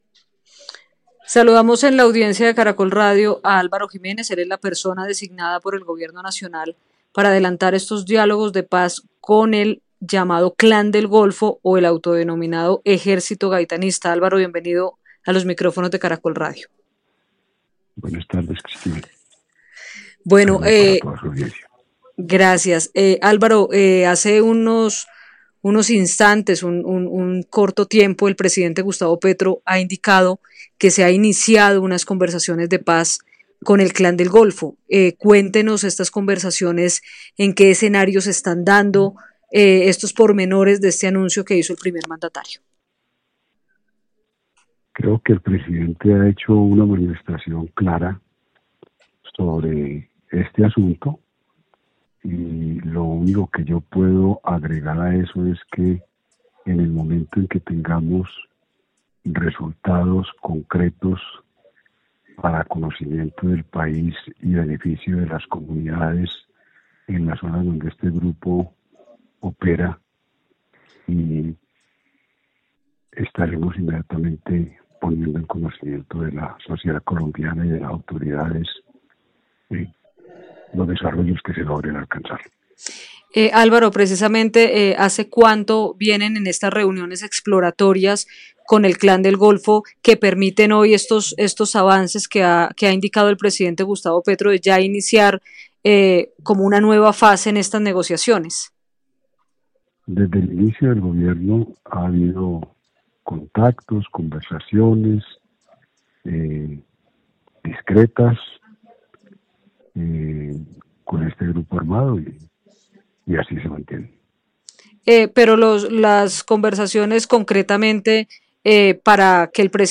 Luego de que el presidente Gustavo Petro anunciara el inicio fuera del Colombia de las conversaciones de paz con el Clan del Golfo, Caracol Radio habló en exclusiva con Álvaro Jiménez, quien es el jefe del equipo negociador del Gobierno en estos diálogos y aseguró que están por llegar a resultados concretos en beneficio de la población civil en las zonas donde este grupo opera.